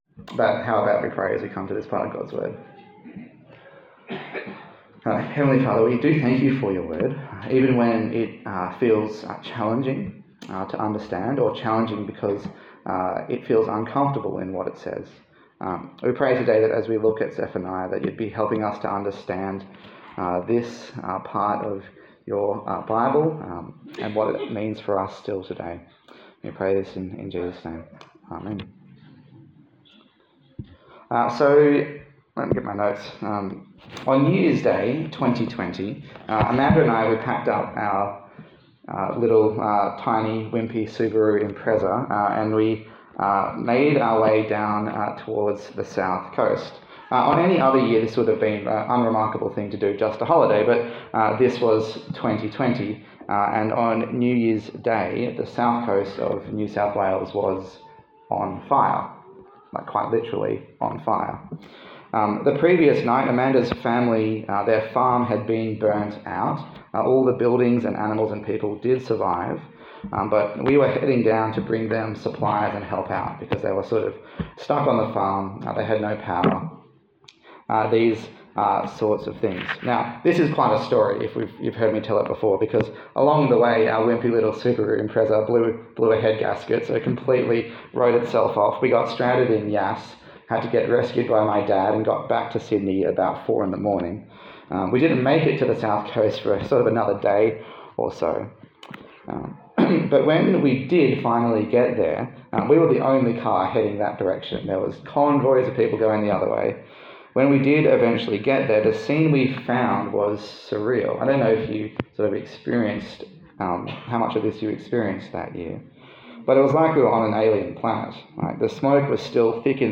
A sermon on the book of Zephaniah
Service Type: Sunday Morning